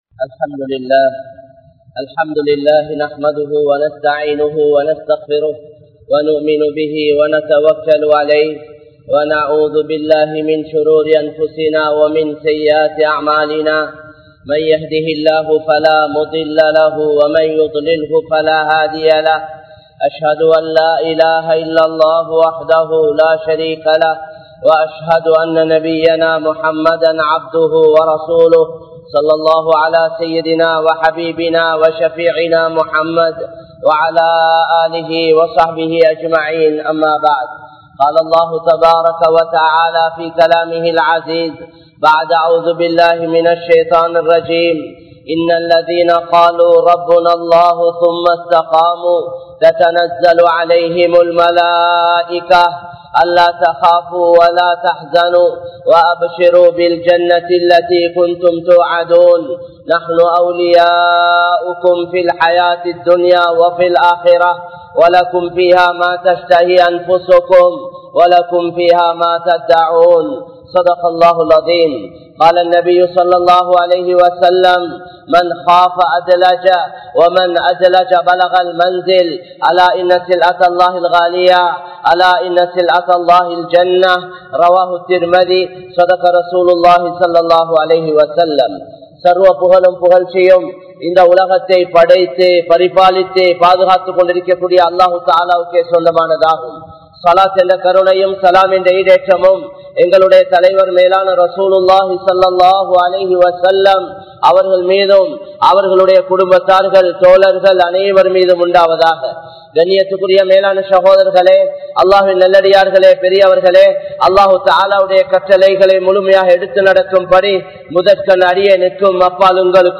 Allah`vai Uruthiyaaha Nampugal (அல்லாஹ்வை உறுதியாக நம்புங்கள்) | Audio Bayans | All Ceylon Muslim Youth Community | Addalaichenai
Colombo 12, Aluthkade, Muhiyadeen Jumua Masjidh